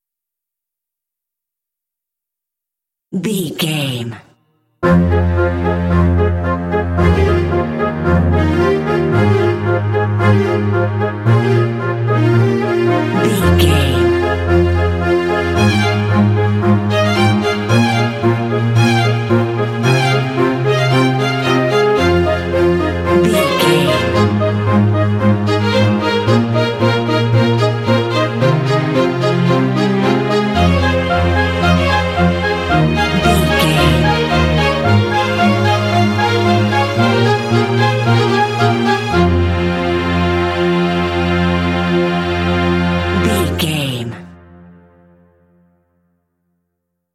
Epic / Action
Aeolian/Minor
dramatic
foreboding
strings
flute
oboe
cinematic
film score